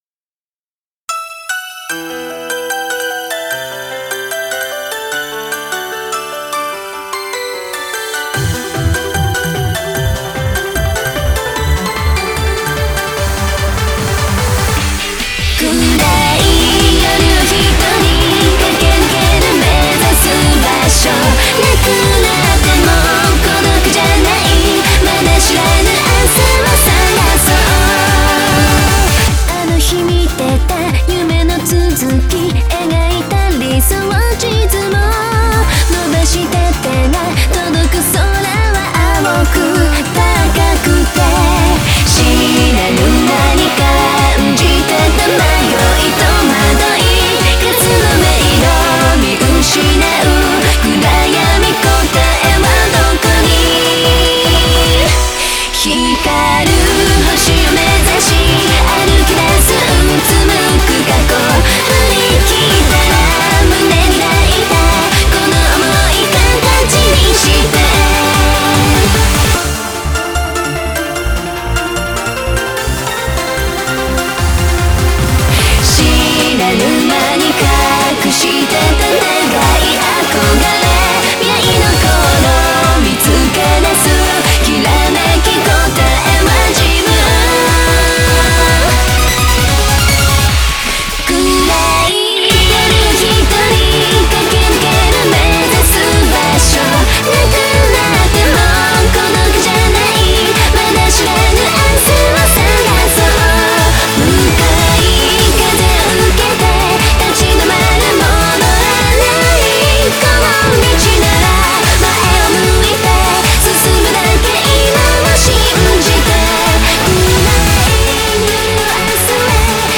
BPM149
Audio QualityPerfect (High Quality)
Genre: SOLITUDE.